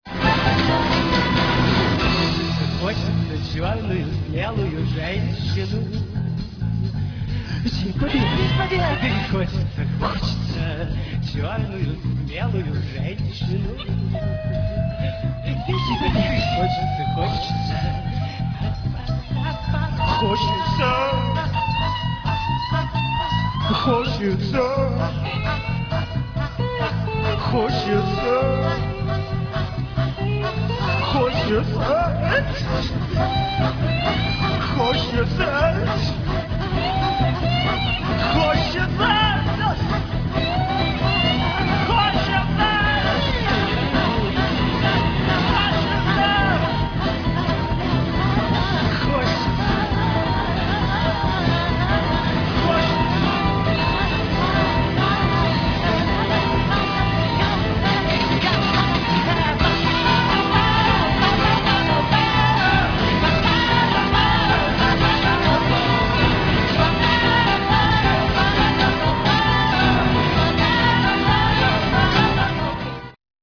Концерт на Шаболовке (1993)
фрагмент песни